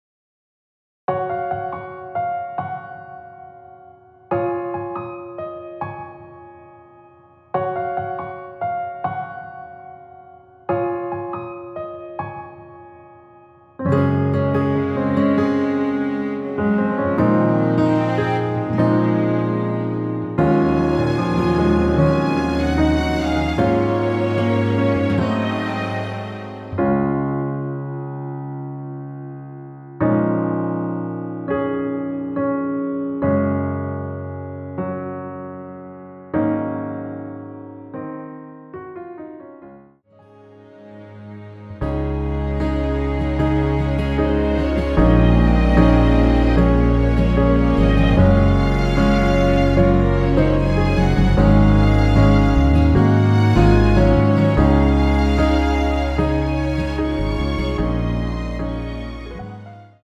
Db
앞부분30초, 뒷부분30초씩 편집해서 올려 드리고 있습니다.
중간에 음이 끈어지고 다시 나오는 이유는